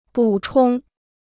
补充 (bǔ chōng): compléter; supplémenter.